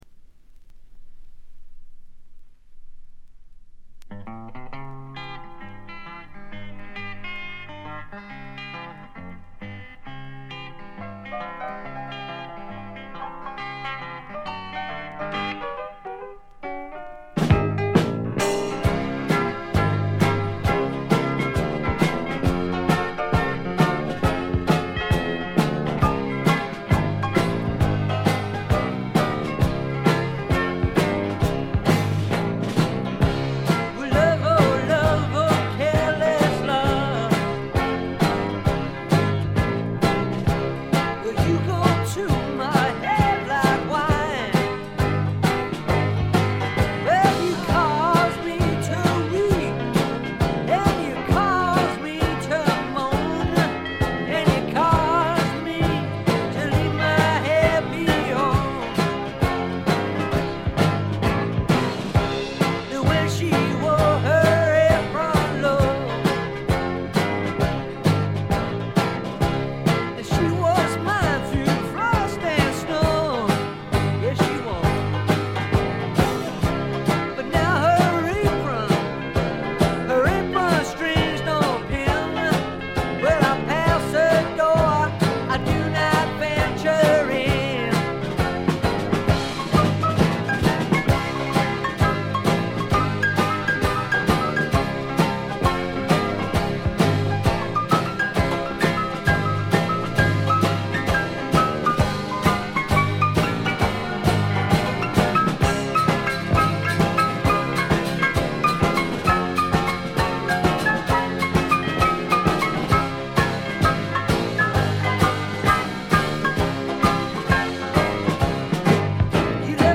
「ブリティッシュ･スワンプ」と呼ばれるジャンルは当然として、英国産ロックの中でも屈指の名盤です。
試聴曲は現品からの取り込み音源です。